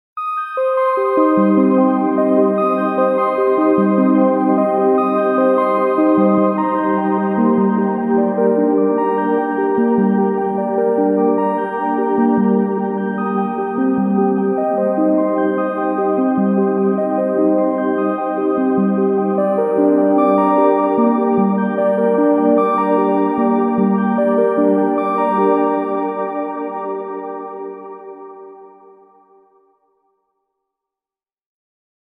Sound Logo Artist